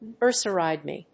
アクセント・音節núrsery・màid